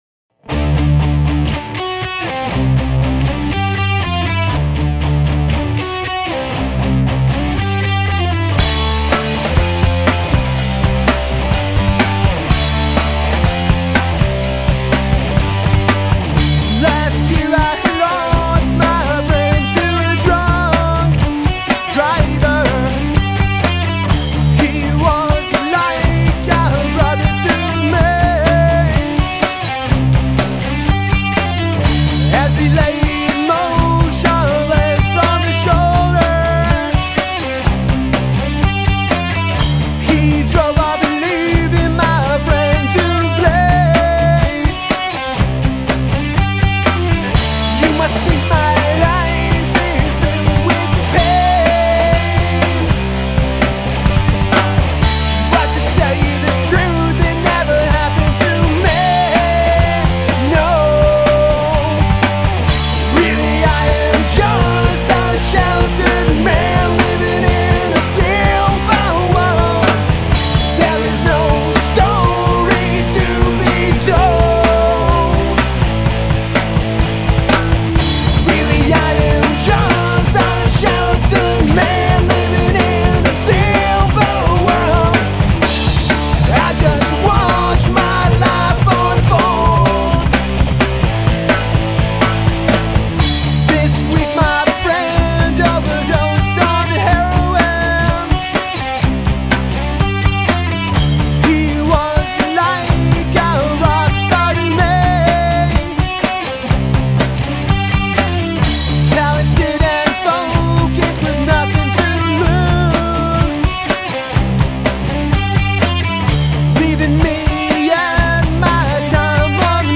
Tunes to Rock Out